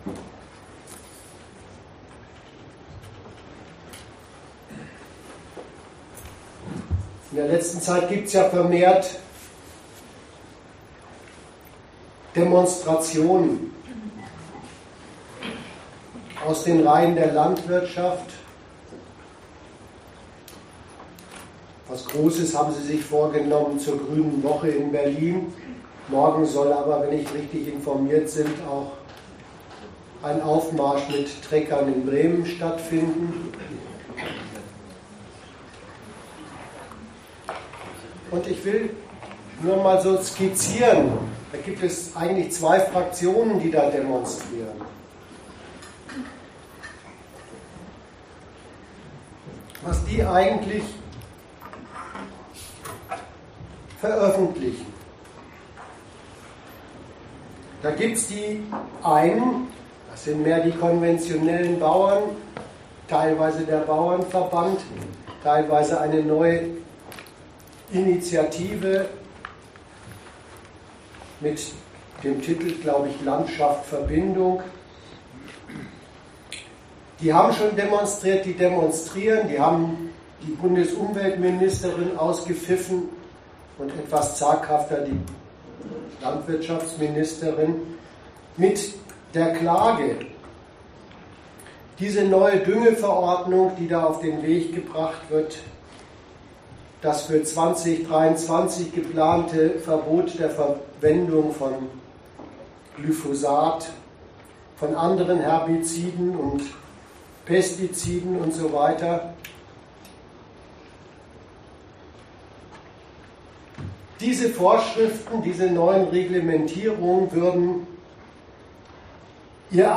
Dozent Gastreferenten der Zeitschrift GegenStandpunkt